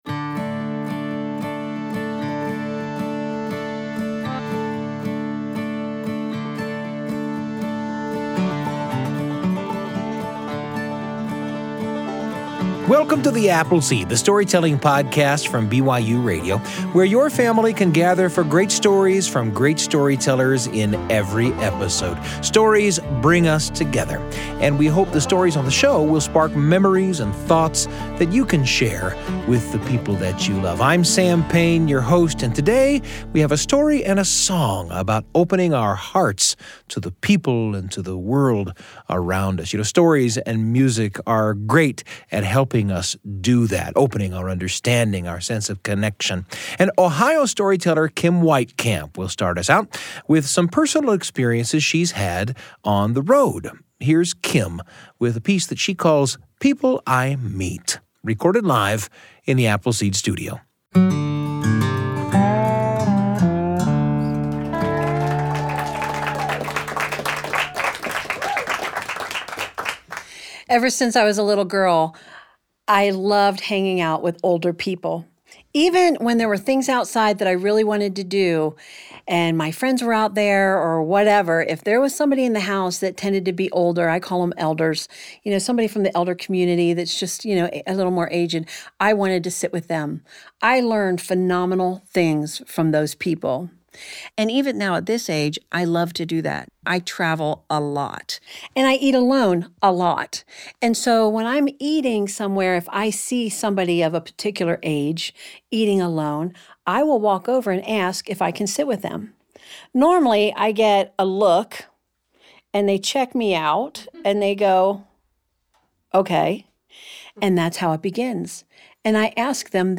The Apple Seed is a storytelling podcast by BYUradio